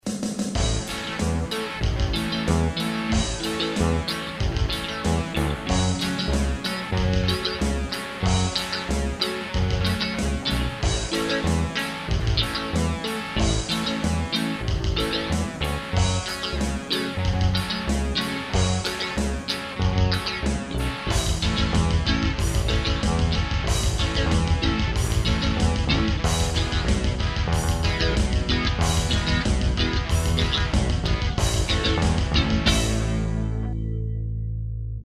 Записали сегодня с другом темку. Вообще, изнычально она была упражнением на двуручный тэппинг (простыми словами - звукоизвлечение сразу двумя руками на грифе (обычно, одна рука зажимает на нём лады, другая дёргает струну, тут - обе играют на грифе)), но я дописал туда барабаны и бас. А друг предложил ещё и аккустическую гитару.
Грязновато немного, но вроде бы, неплохо.